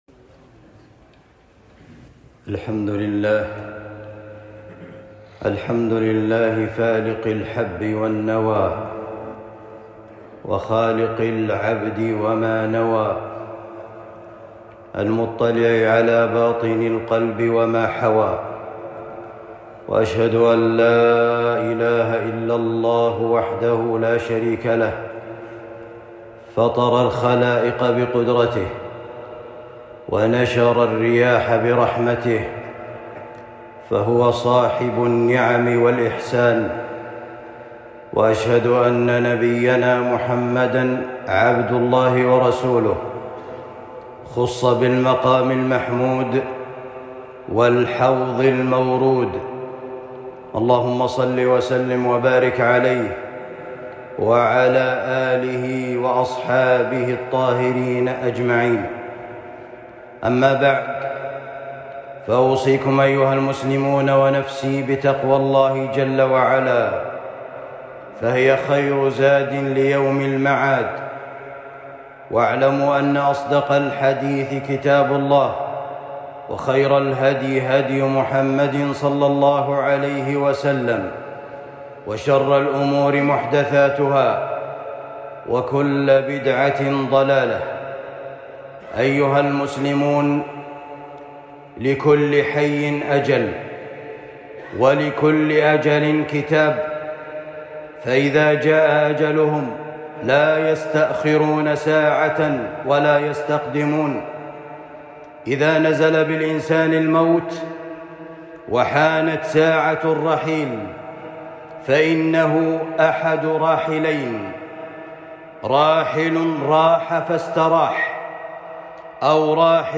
مستريحٌ ومستراحٌ منه خطبة جمعة